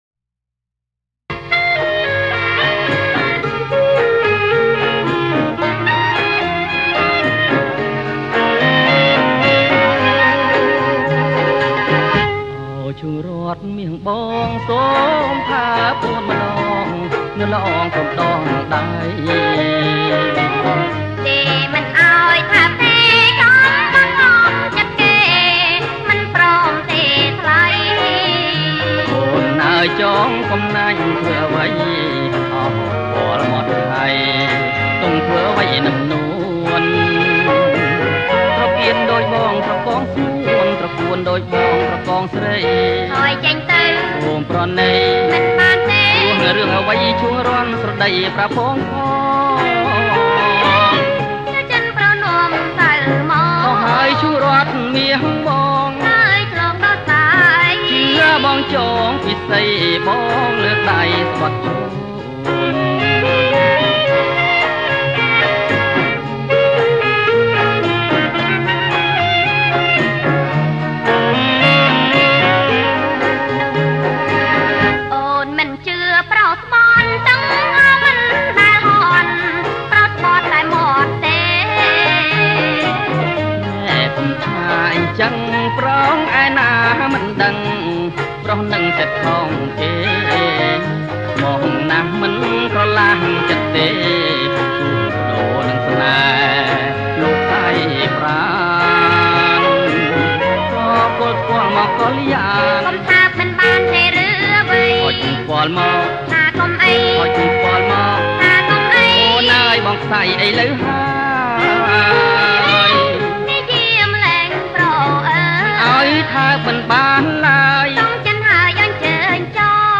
ប្រគំជាចង្វាក់ Boler Kbach